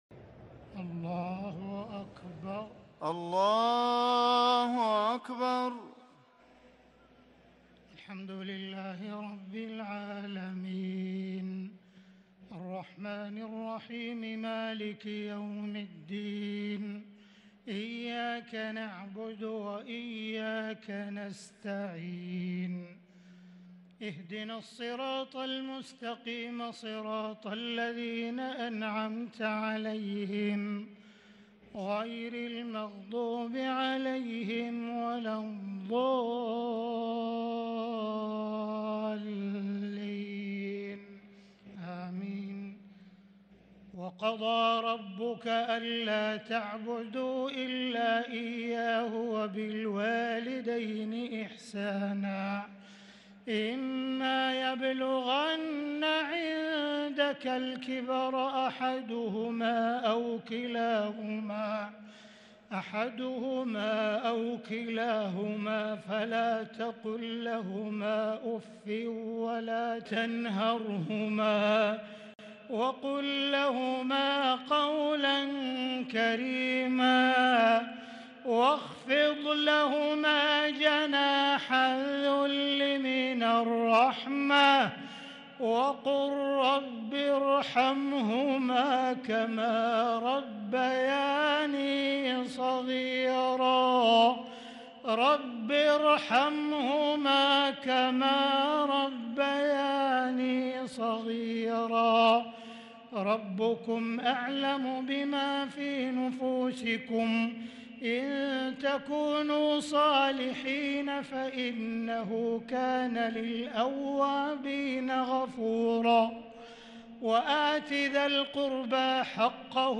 تراويح ليلة 19 رمضان 1443هـ من سورة الإسراء (23-48) | taraweeh 19 st niqht Surah Al-Israa 1443H > تراويح الحرم المكي عام 1443 🕋 > التراويح - تلاوات الحرمين